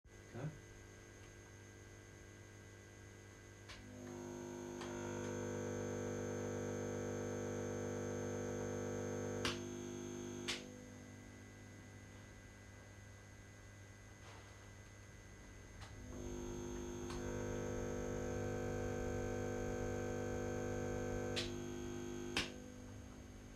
Electric Blanket making Multi Buzz
Off grid house, Multi 48/5000 been running well for 4 years, owner added electric blanket and when its on, the Multi makes a buzzing noise irritating the owner.
victron-buzz.m4a